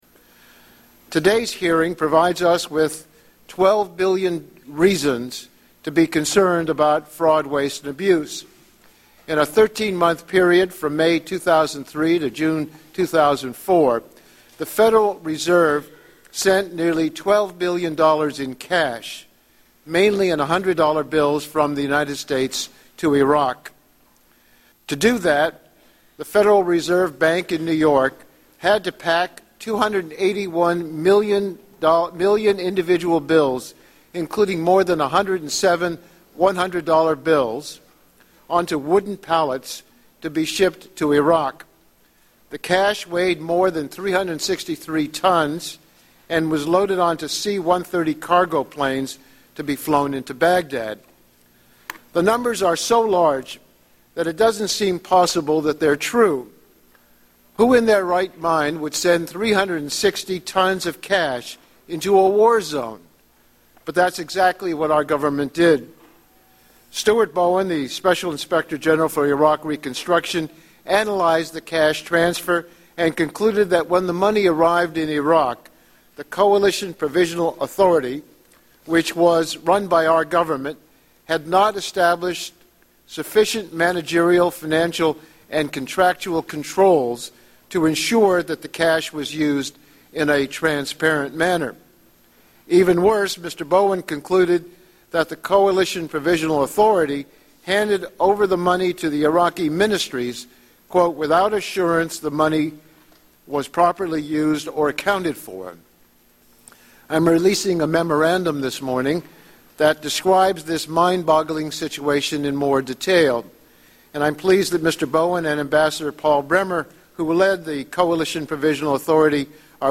Tags: Historical Top 11-20 Censored News Stories 2009 Censored News Media News Report